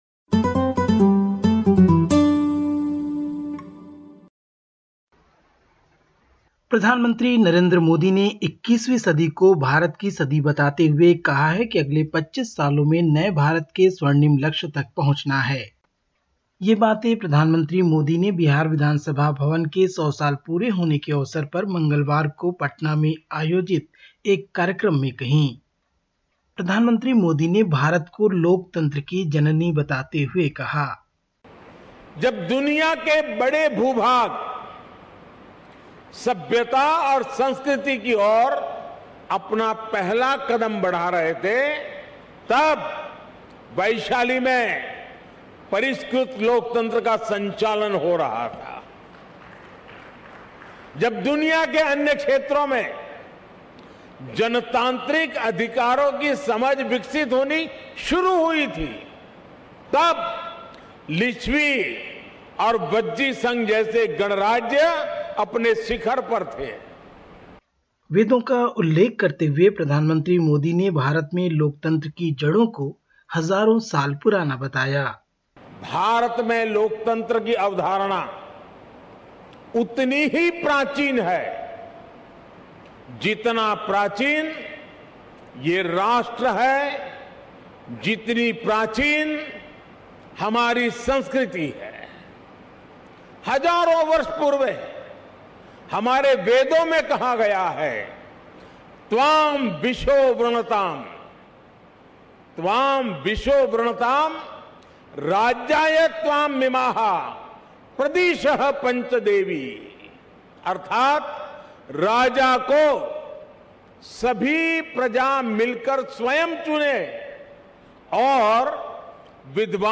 Listen to the latest SBS Hindi report from India. 13/07/2022